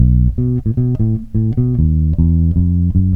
Loops de baixo 42 sons